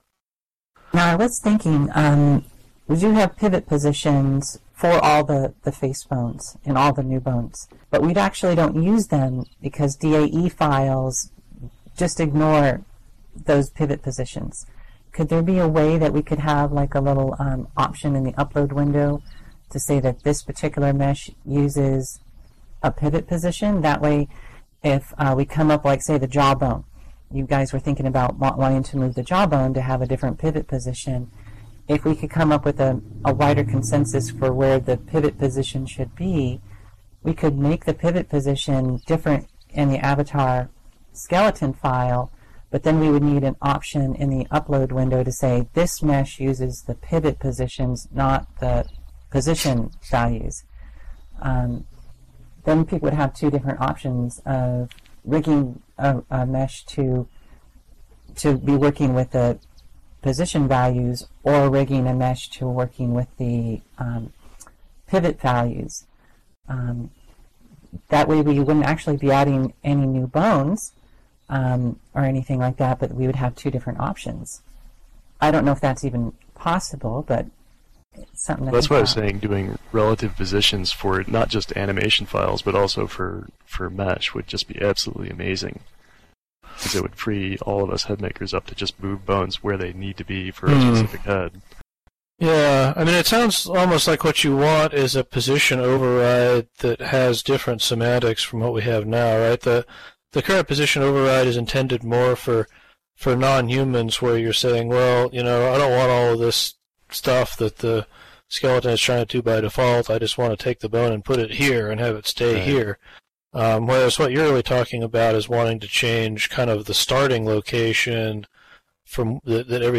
The following notes and audio were taken from the weekly Bento User Group meeting, held on Thursday, June 16th at 13:00 SLT at the the Hippotropolis Campfire Circle .